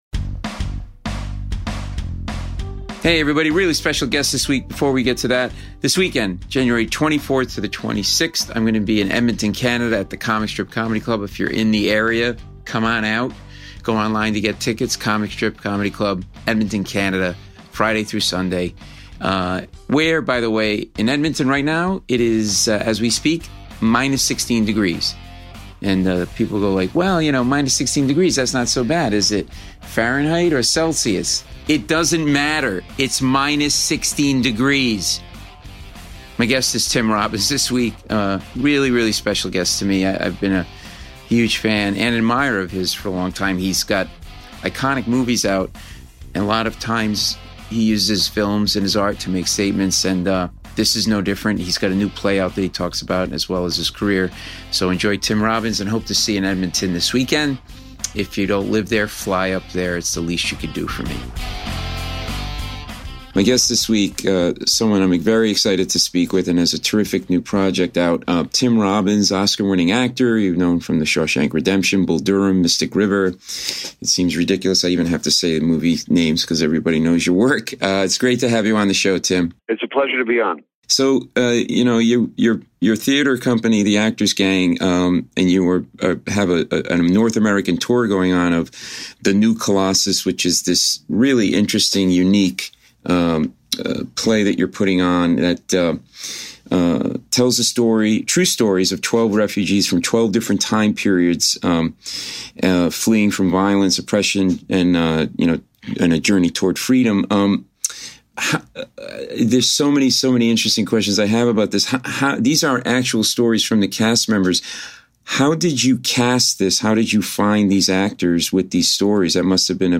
A riveting conversation with one of the biggest stars in Hollywood who's starred in many iconic films.